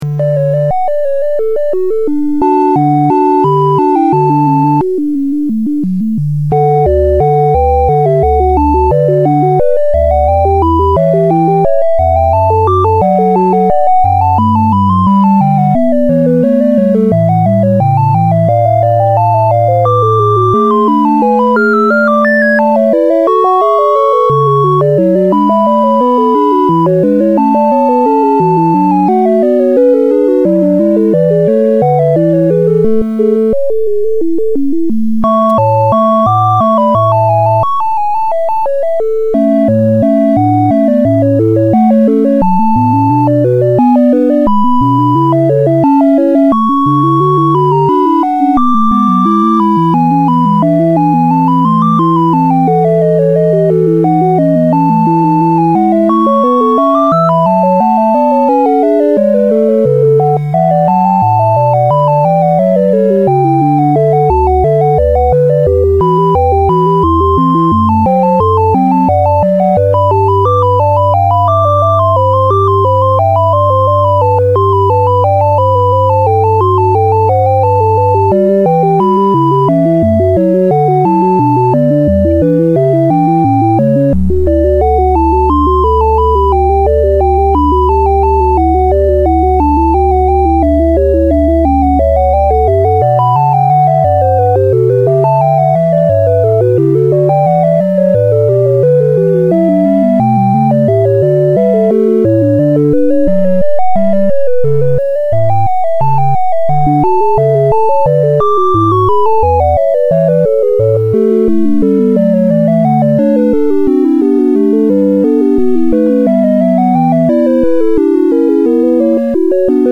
Orgel-Triosonate C-Dur) umwandeln und abspielen kann.
Das Abspielen oder Schreiben als WAV- oder FMS-Datei übernimmt der Synthesizer.
trio.mp3